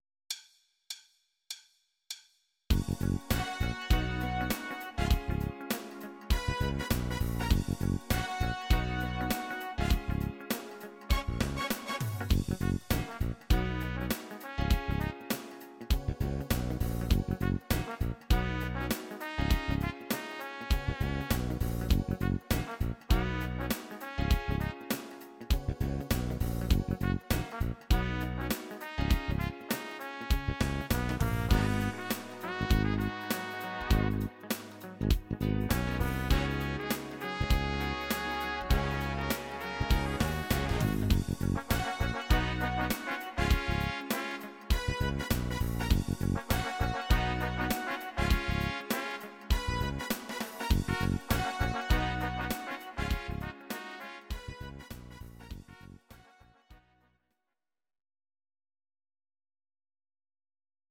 Audio Recordings based on Midi-files
Pop, 1990s